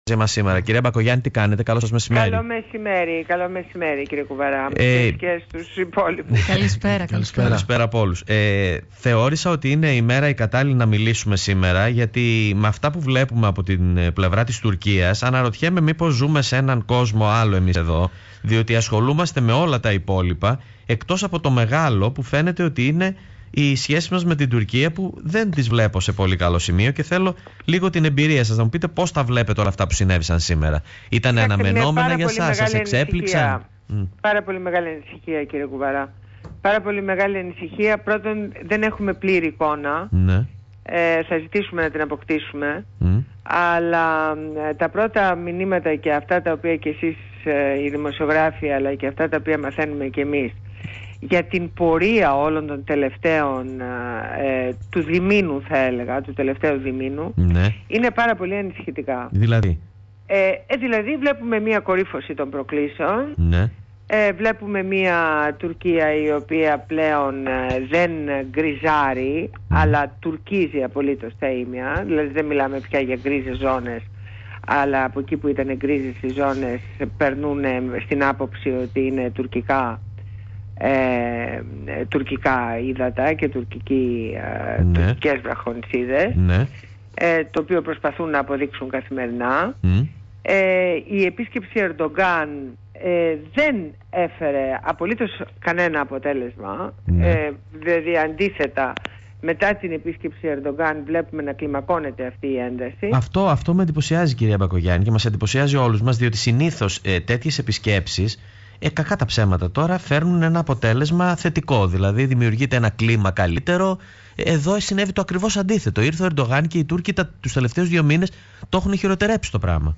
Συνέντευξη στο ραδιόφωνο Θέμα 104.6